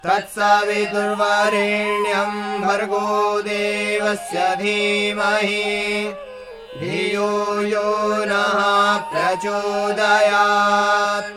Vedic chanting
मन्त्र उच्चारण